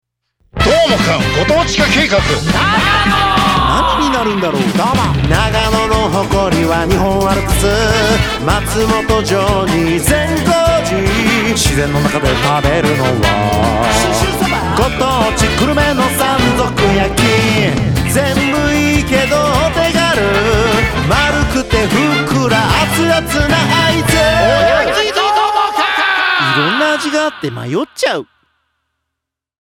SONG ROCK / POPS